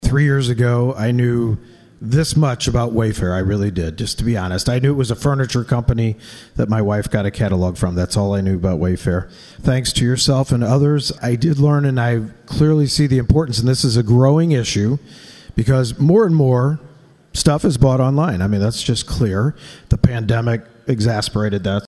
During debate, St. Louis Democrat Steve Butz talked about the proposal that would include income tax cuts to help offset the projected sales tax revenue increase.